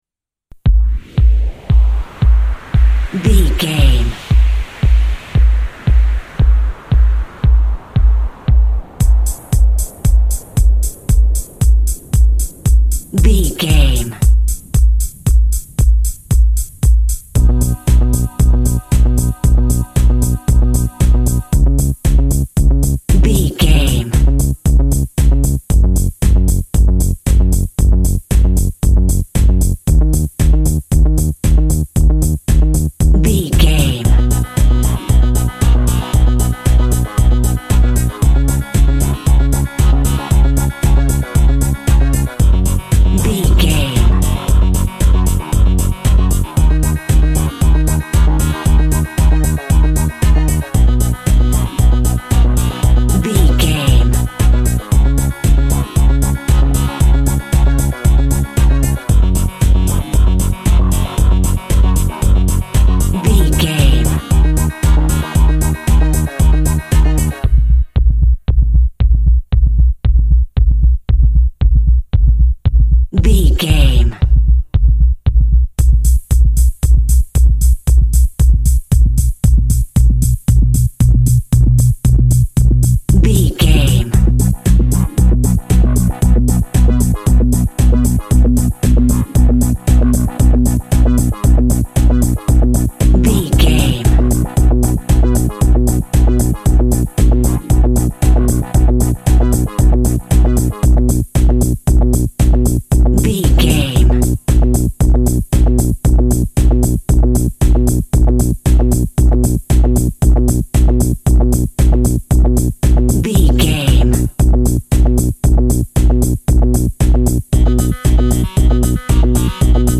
Eighties Pop House.
Aeolian/Minor
E♭
uplifting
energetic
bouncy
chilled
techno
dance instrumentals
synth lead
synth bass
Electronic drums
Synth pads